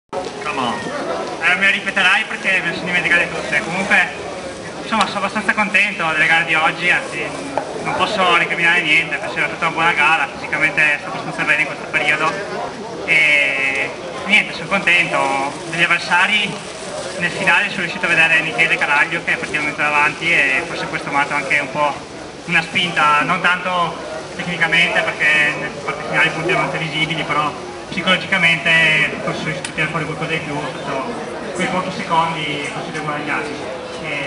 28.2.2009 - Campionati italiani sprint a Firenze
intervista